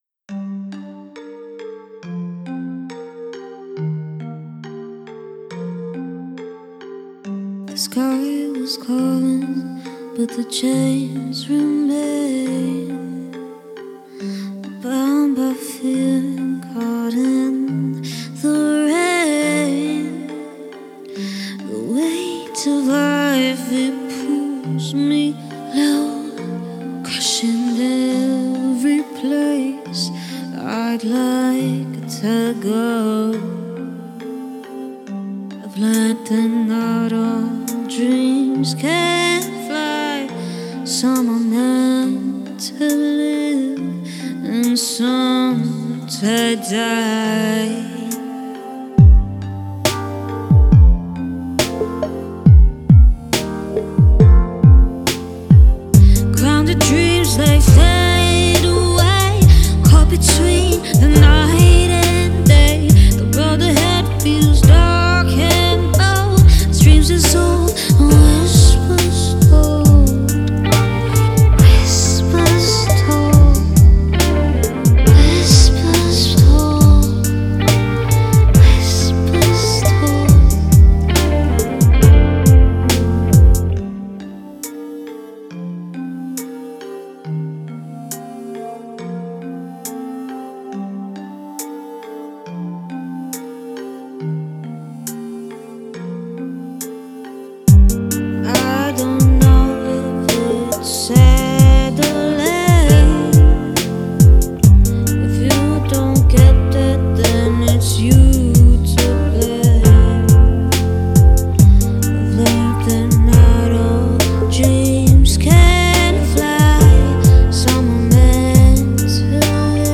Electronic Rock, Pop + Hiphop with indian vibes